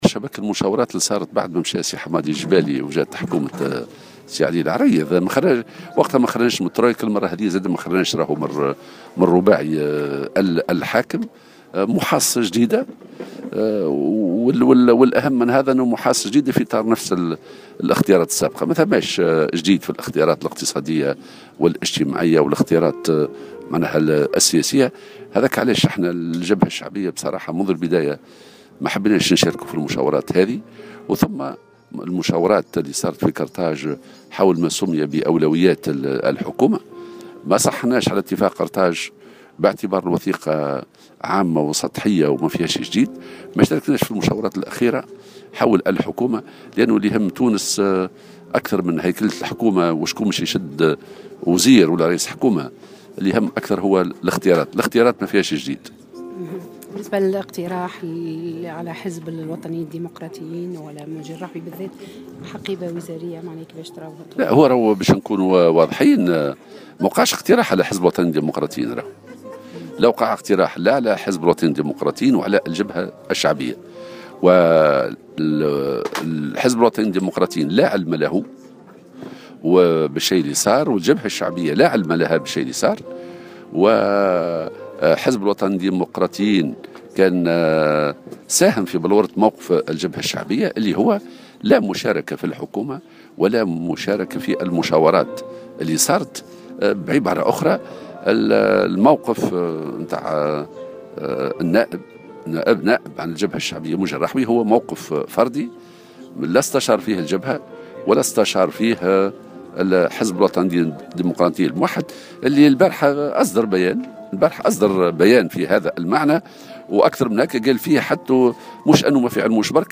وقال في تصريح لمراسلة "الجوهرة أف أم"، إن الجبهة الشعبية والوطد لا علم لهما بهذا الاقتراح وإن ما صرح به منجي الرحوي هو موقف فردي والجبهة غير معنية به.